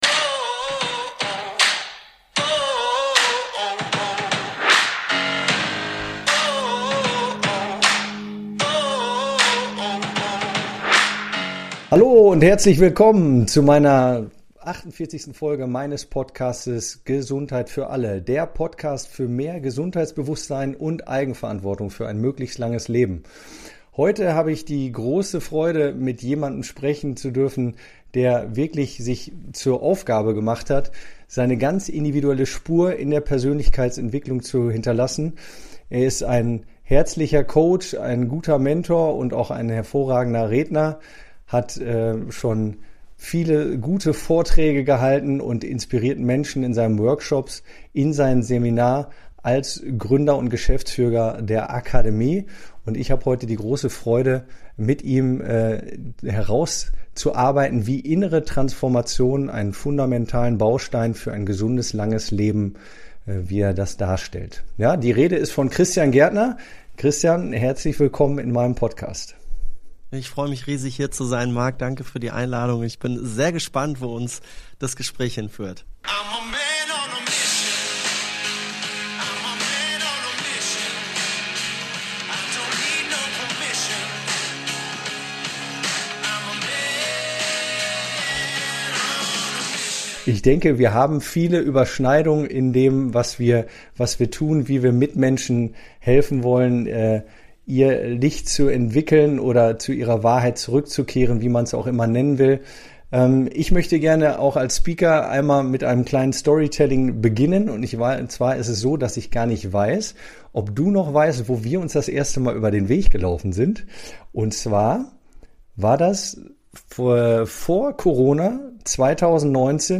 Interview ~ Gesundheit für ALLE! Podcast